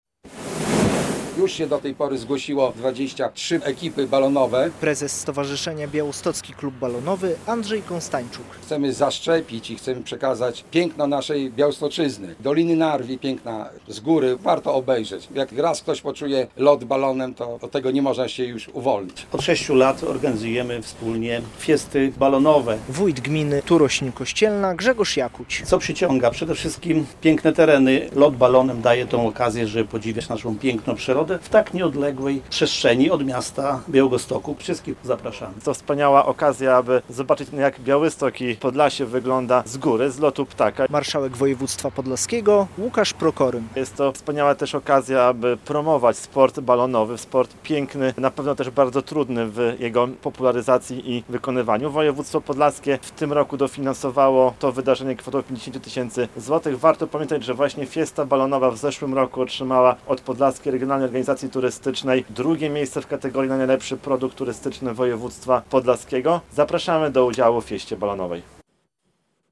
Zbliża się VI Fiesta Balonowa - relacja